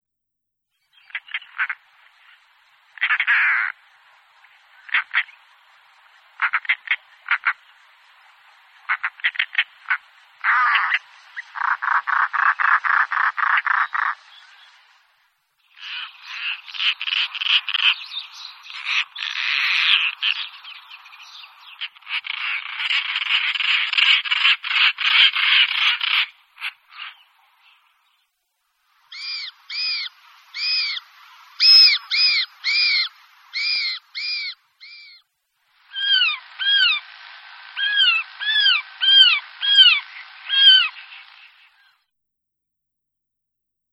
Kraska - Coracias garrulus
głosy